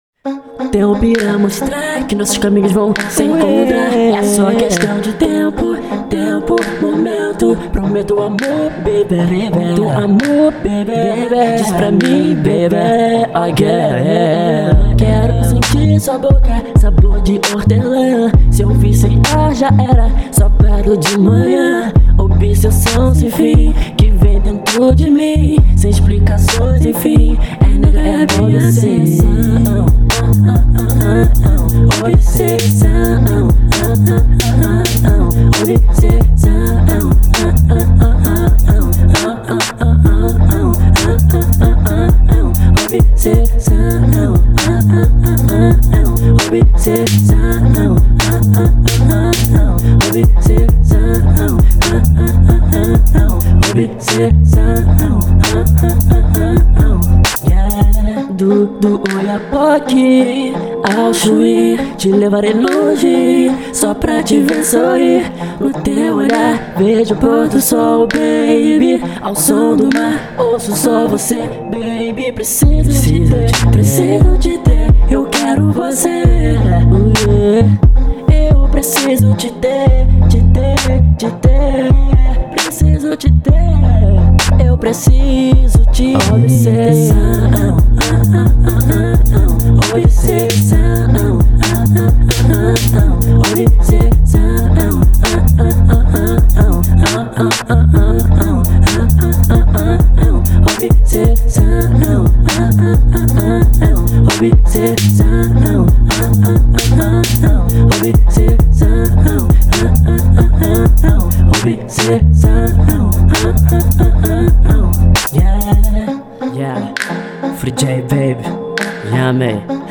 Rock Instrumental Energia Festa Rock